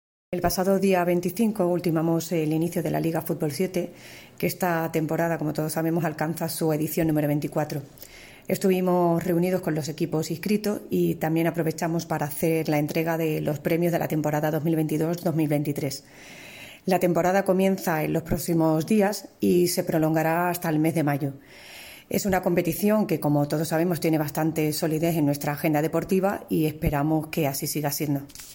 Declaraciones de la concejala María José Pérez